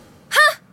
hmmpf_ELEAM3U.mp3